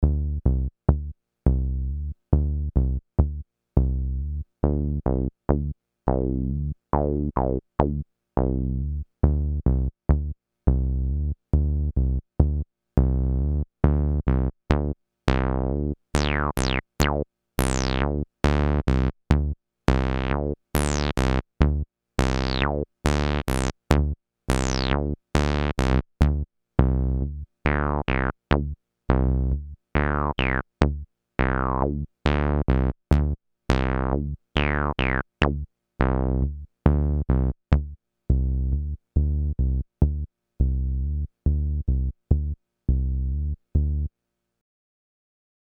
Roland TB-303 Bassline
Basic 16 step 5 note pattern with slide and accent
Pattern sweeps through the filter, resonance, envelope, and decay
No EQ, No compression
The results above are raw audio, without eq, compression, or treatment.